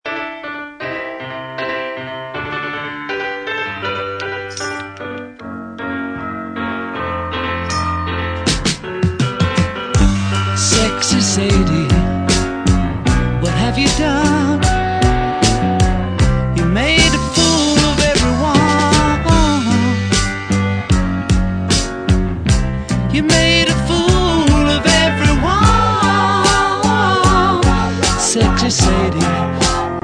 Studio Recorded: EMI & Trident Studios, London